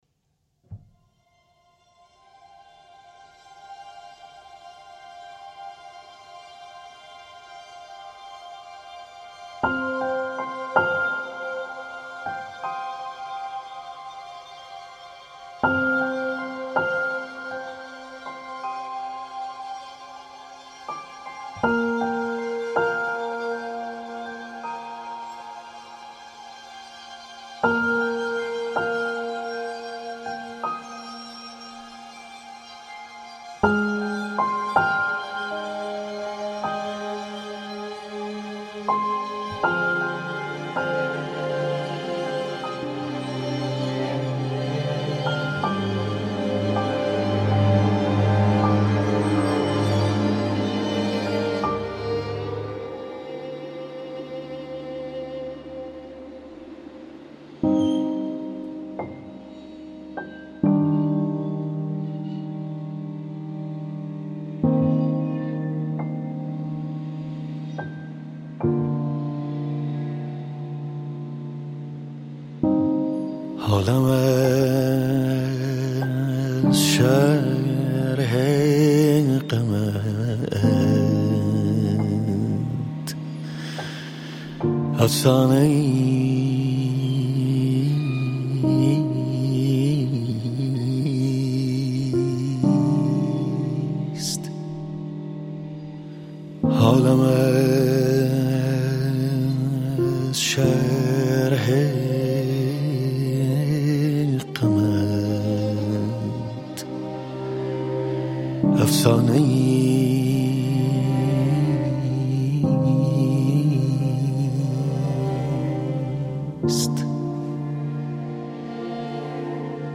موسیقی غربی، به ویژه موسیقی کلاسیک و امبینت است.